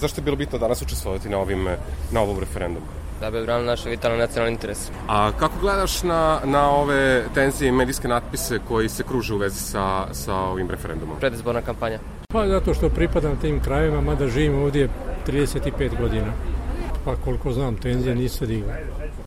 Anketa: glasači u Novom Sadu